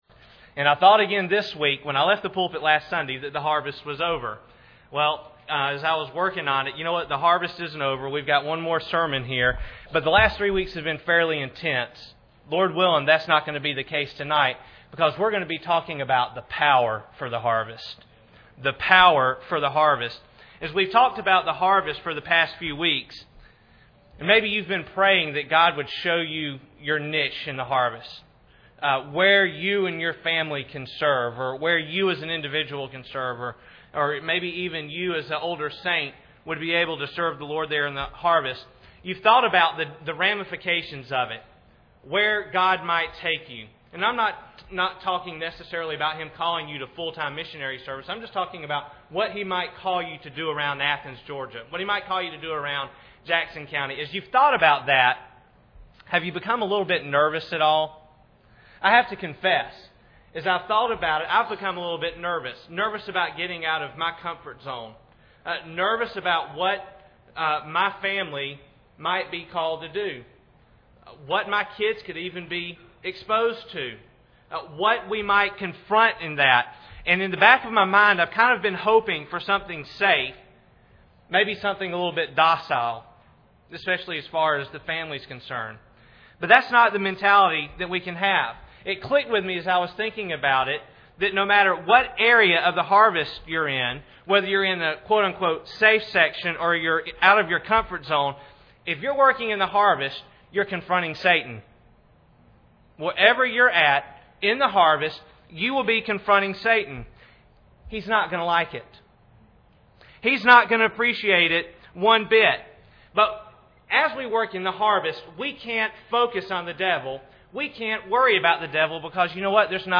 Passage: Mark 5:1-20 Service Type: Sunday Evening